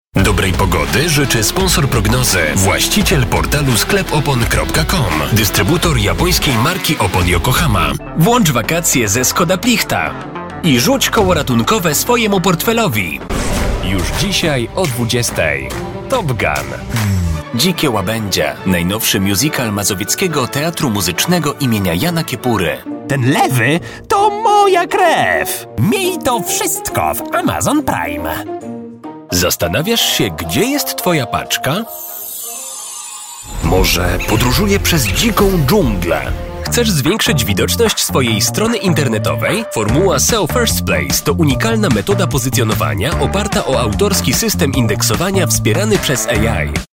Prezentujmy jeden z bardzo ciekawych głosów lektorskich, który z całą pewnością przypadnie każdemu do gustu.